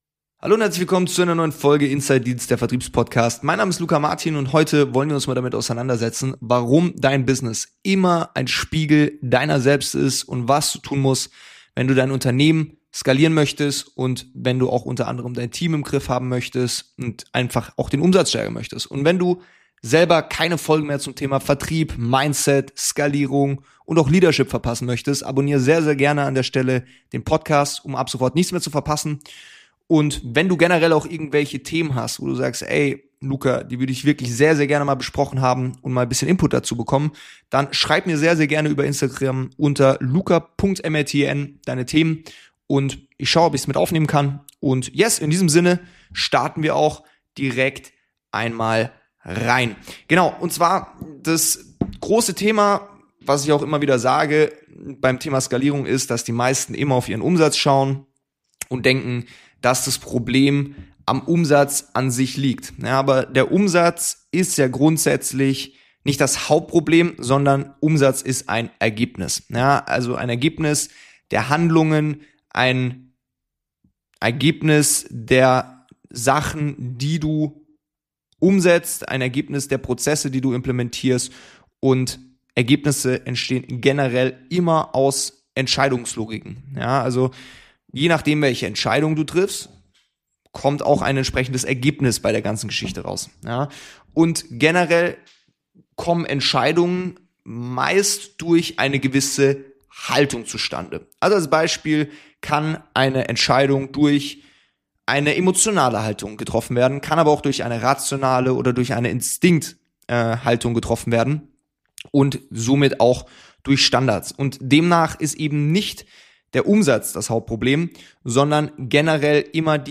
Eine ruhige, aber klare Folge über Verantwortung, Reife und die Fähigkeit, sich selbst ehrlich zu hinterfragen.